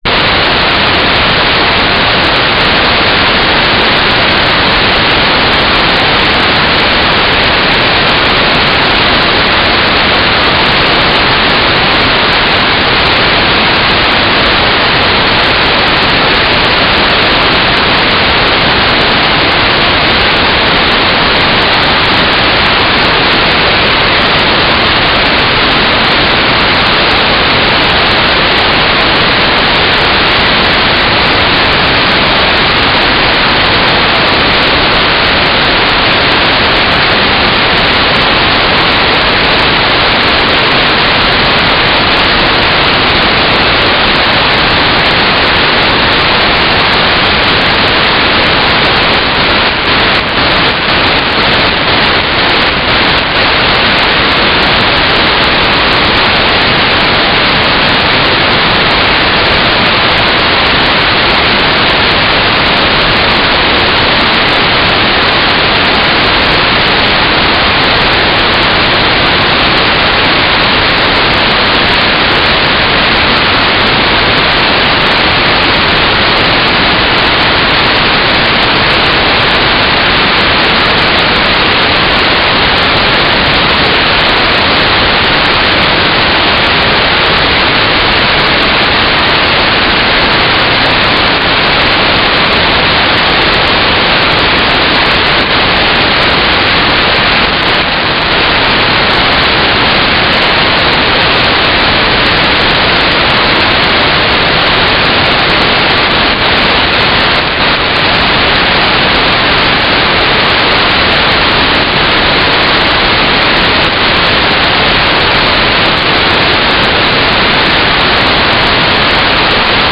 "transmitter_description": "Mode U - GFSK4k8 - AX.25 - Telemetry",